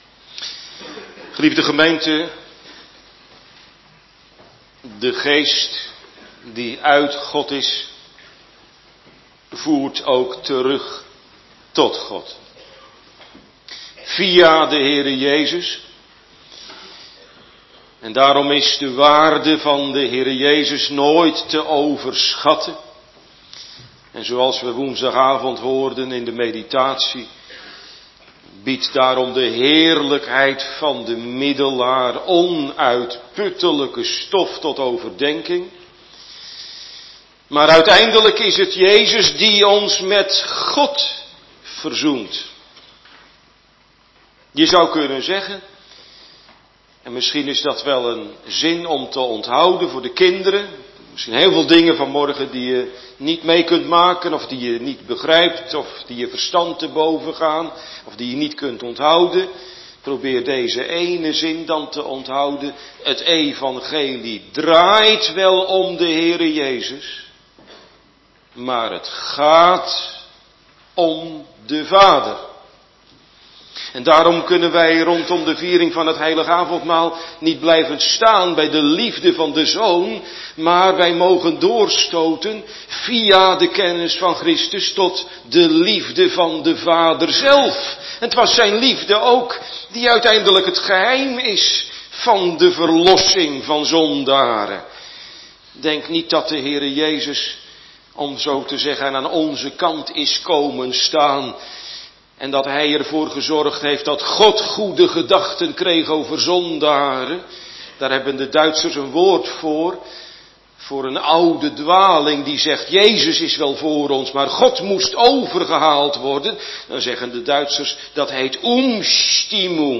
Organist